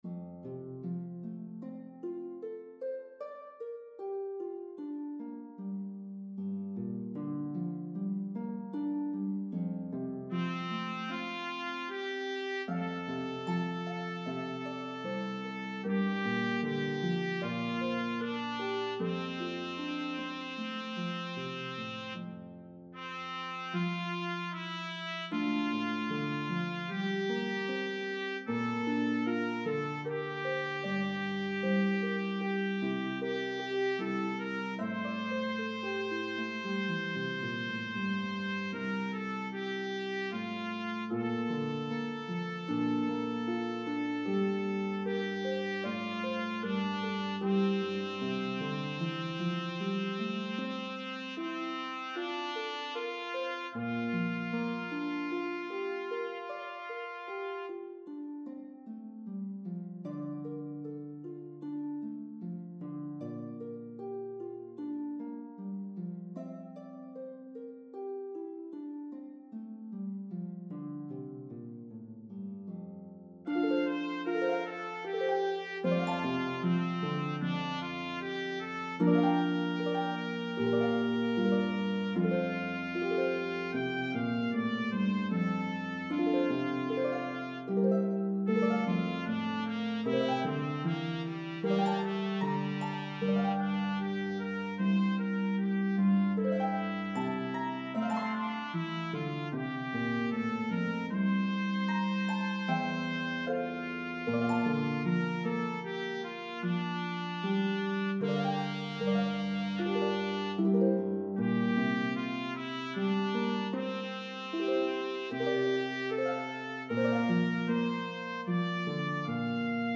Harp and Trumpet in B-flat version